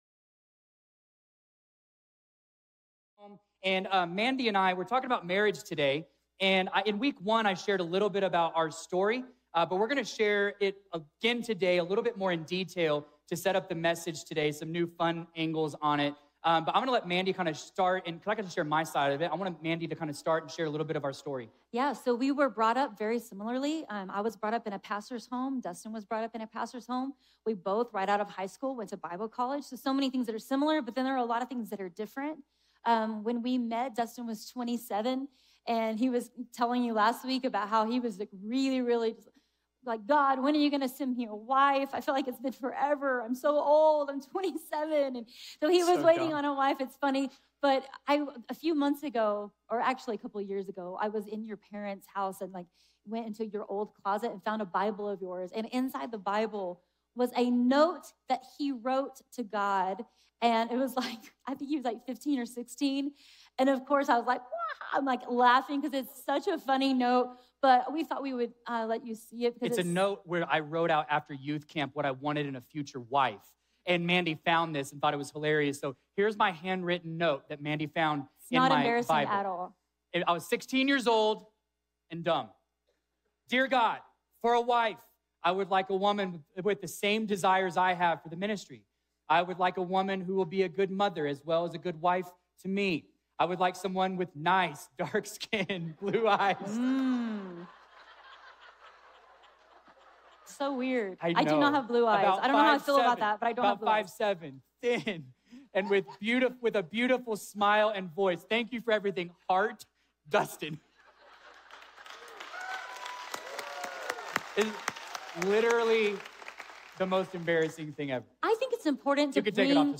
Welcome to Week 1 of our brand-new sermon series, Home Sweet Home! In this series, we’re diving into God’s design for the family — marriage, children, parenting, singleness — and how every season of life can flourish when Jesus is at the center of our home.